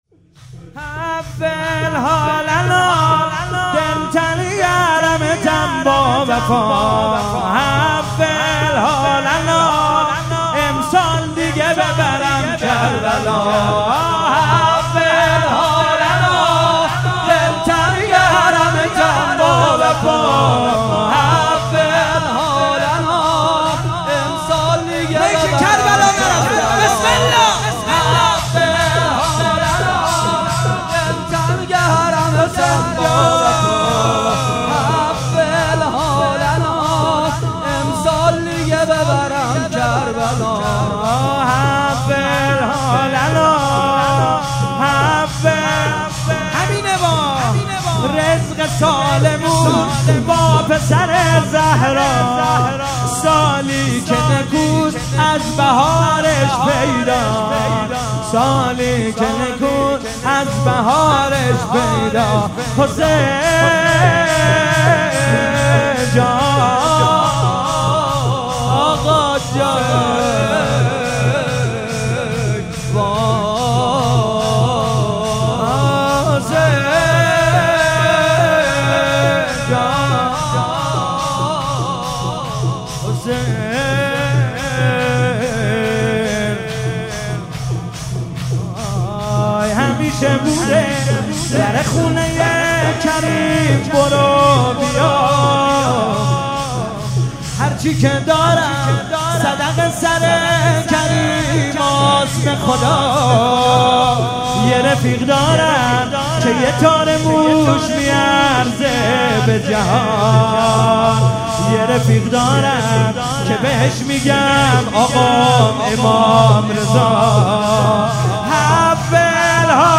میلاد امام جواد(ع)